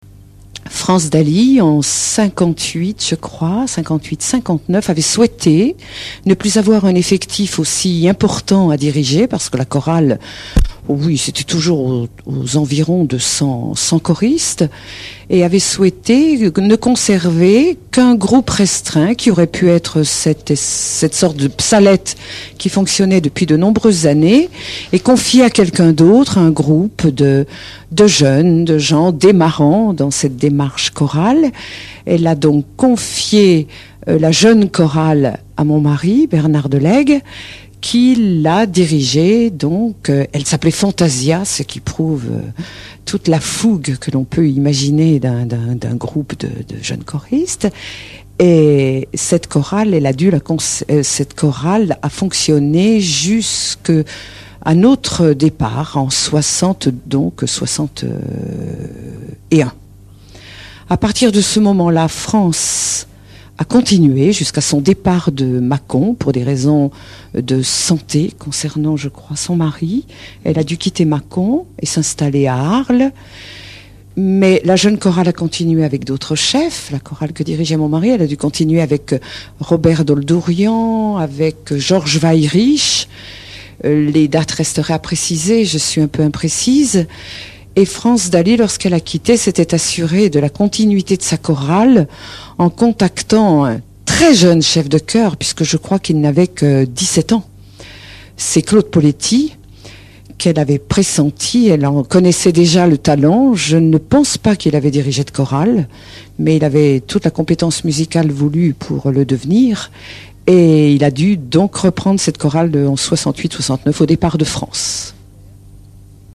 Interview Radio